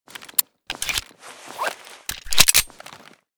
fnp45_reload_empty.ogg.bak